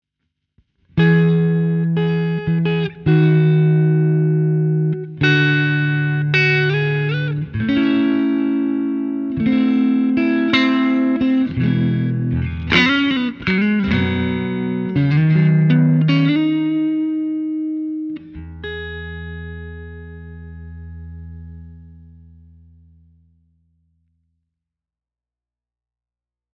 All audio clips are recorded with a Marshall JCM900 head, set on an extremely clean tone. The cabinet used is a 2×12 openback with Celestion Creamback 75 speakers.
Clean sound, no effects added
Guitar: Fender
Mode: Twin
Gain: 6/10